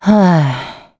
Hilda-Vox_Sigh.wav